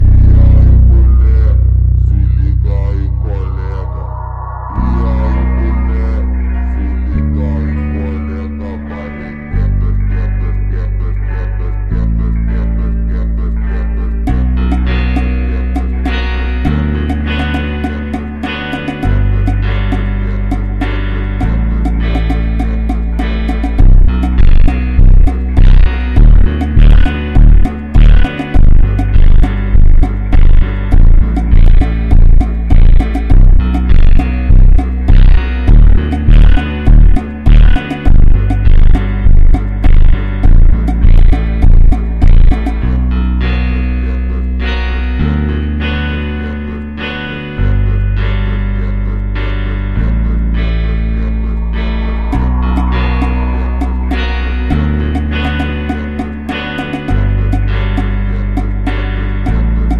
Lamborghini Aventador sitting nicely ❤ sound effects free download